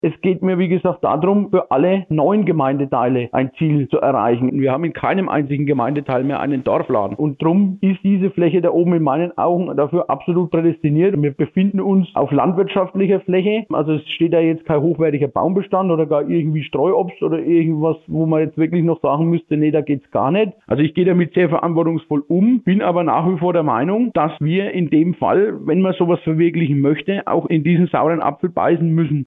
Interview: Das neue Gewerbegebiet "Zeller Berg" in Üchtelhausen - Statement von Bürgermeister Johannes Grebner - PRIMATON